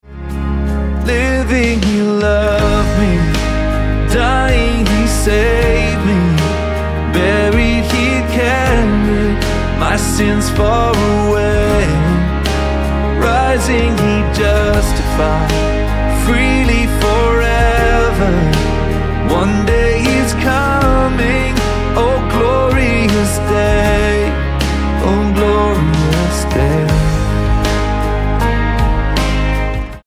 Ab